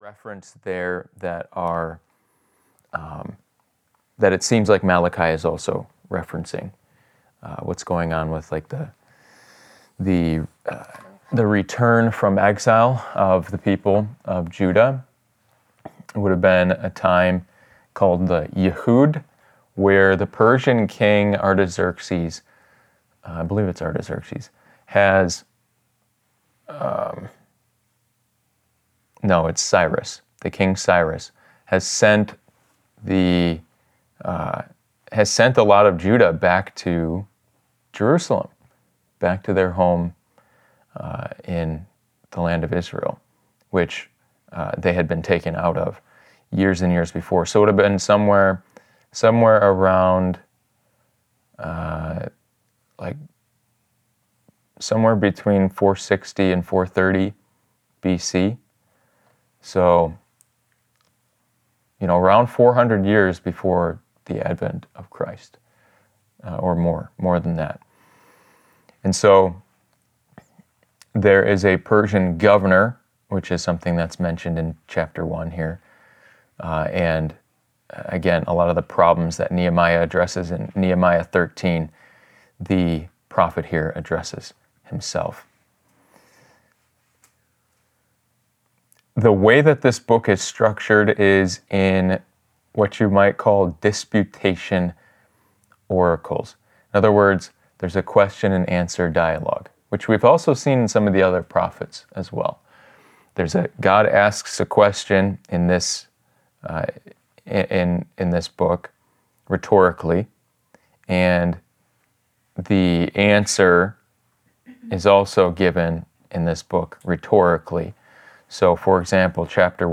Wednesday Bible Lesson